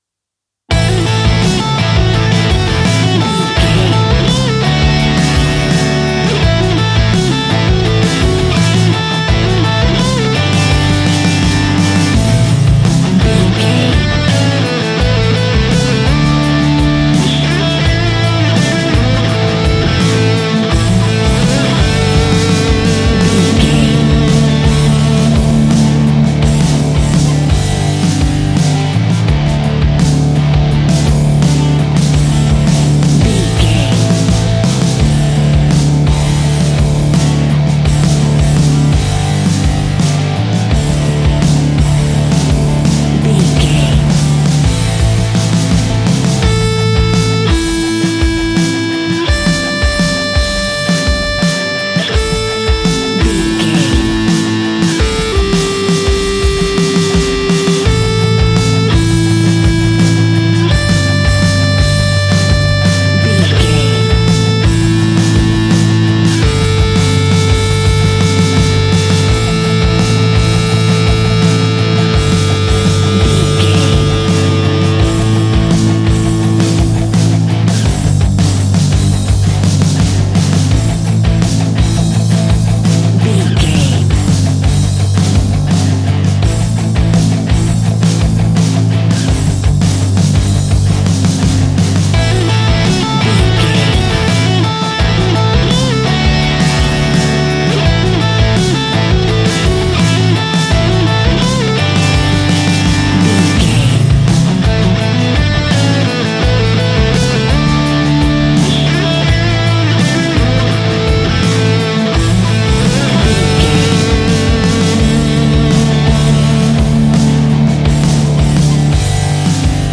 Epic / Action
Dorian
hard rock
blues rock
distortion
instrumentals
Rock Bass
heavy drums
distorted guitars
hammond organ